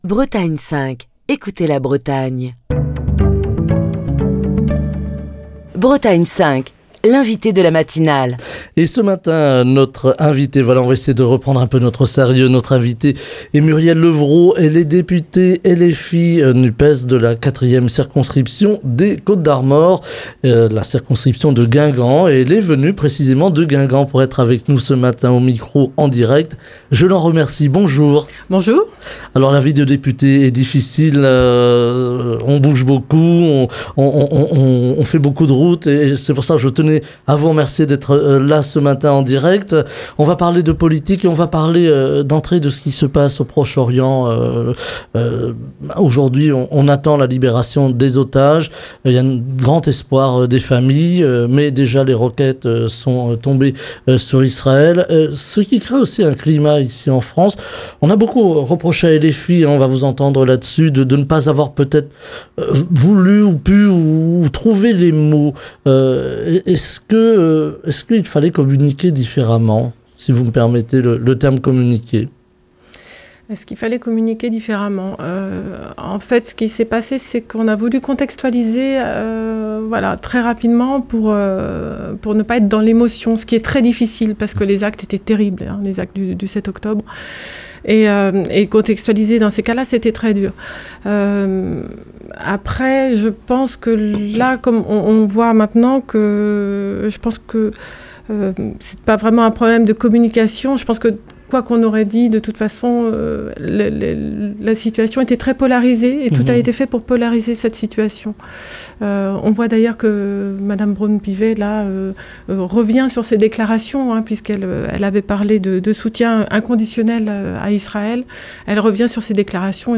Ce vendredi Murielle Lepvraud, députée LFI de la 4ème circonscription des Côtes d'Armor, est l'invitée de Bretagne 5 Matin pour évoquer le conflit Israélo-palestinien et ses conséquences politiques en France, le climat social et l'inflation, la santé avec la fermeture programmée de la maternité de Guingamp et les menaces qui pèsent sur l'hôpital de Carhaix ou encore la fermeture annoncée du collège de Corlay (22).